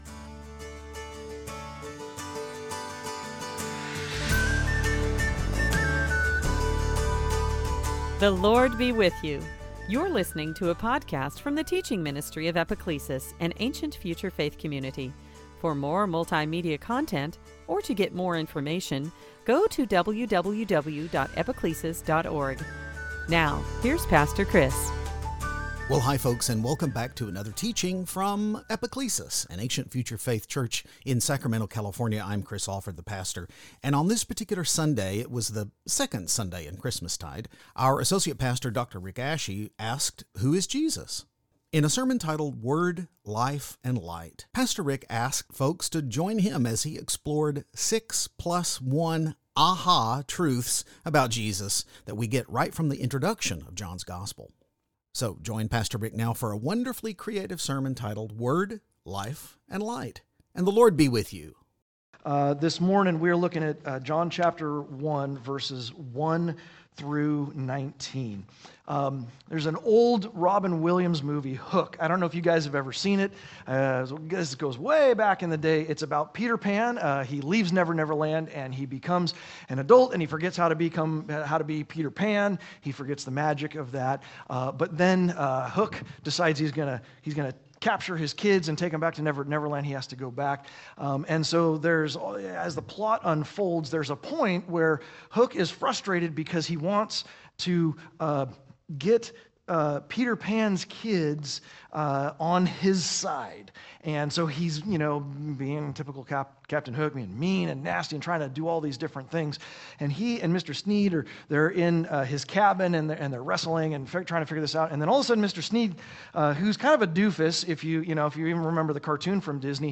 Sunday Teaching Passage